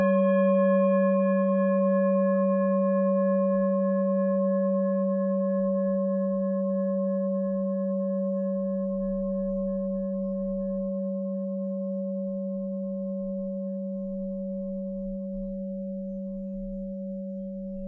Klangschalen-Typ: Bengalen
Klangschale 1 im Set 12
Klangschale N°1
(Aufgenommen mit dem Filzklöppel)
klangschale-set-12-1.wav